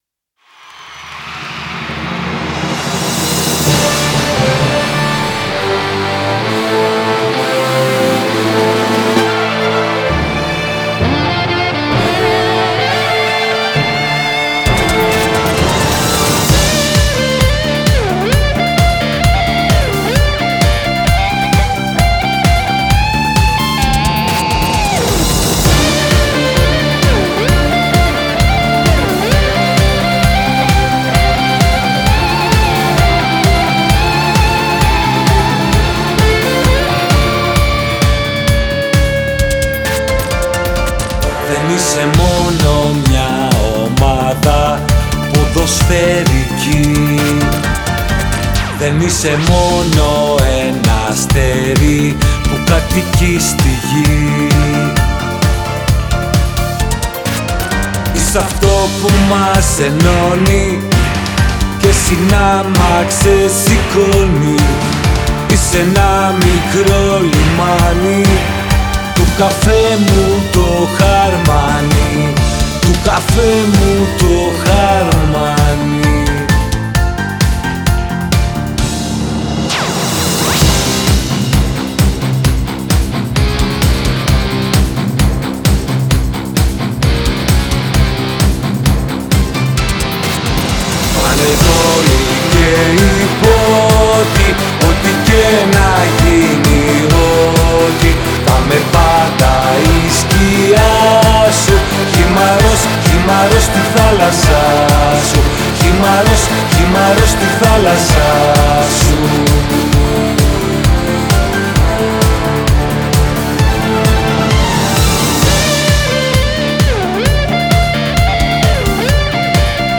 Ηλεκτρική Κιθάρα